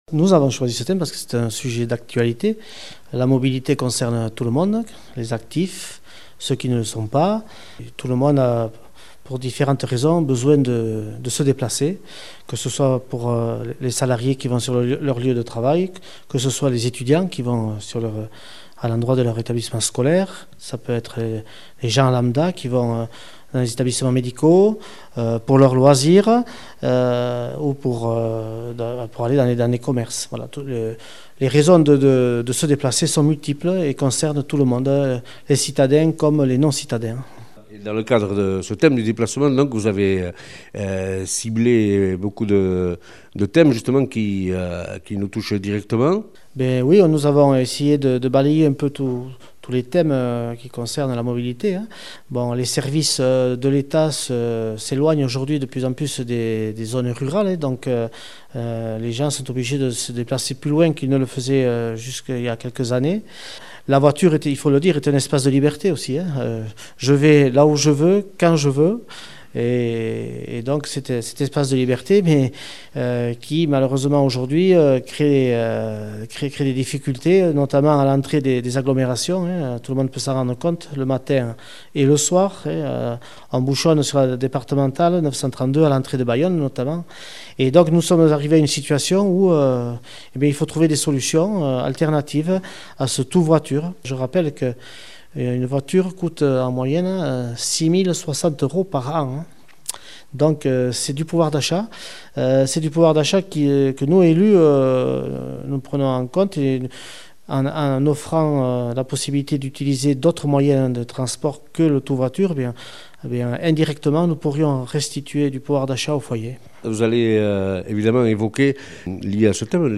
Interrogé au mirco d'Irulegiko Irratia
• Interrogé par Irulegiko Irratia